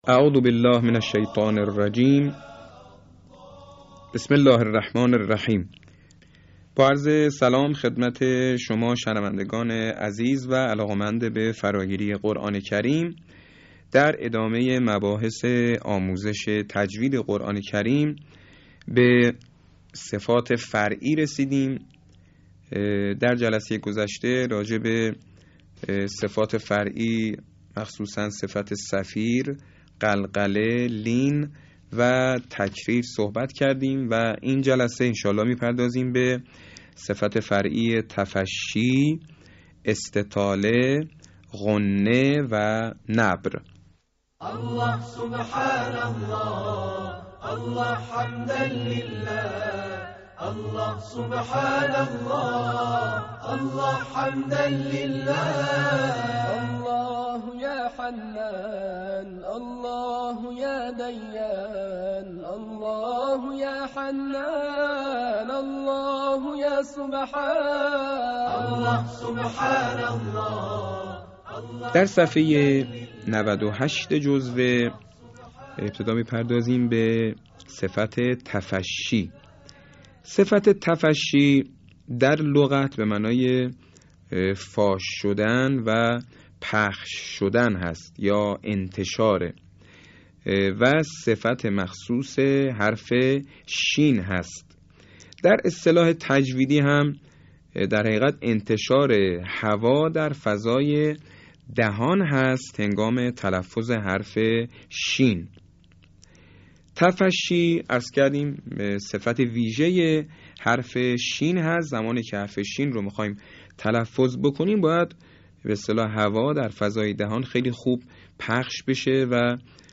صوت | آموزش تجویدی صفات فرعی حروف